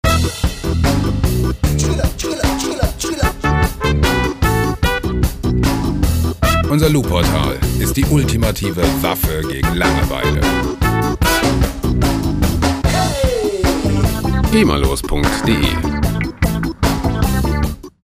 Musikstil: Ska
Tempo: 150 bpm